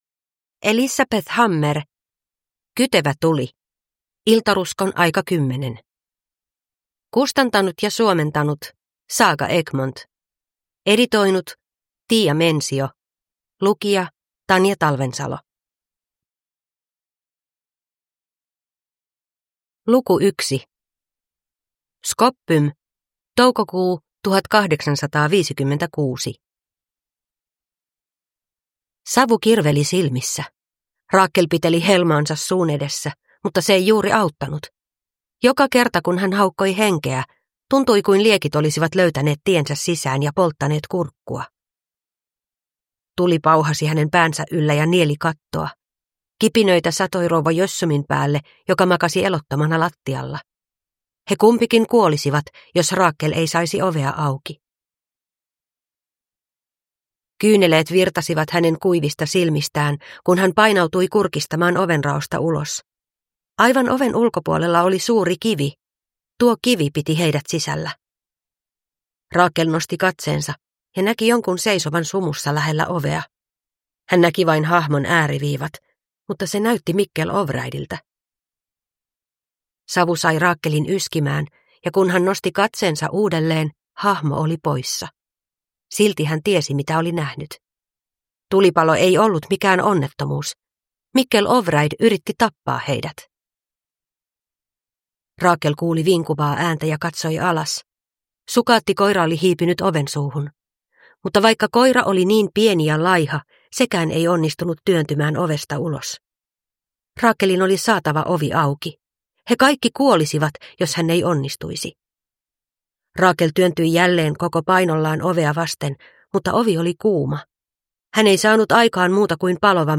Kytevä tuli – Iltaruskon aika 10 – Ljudbok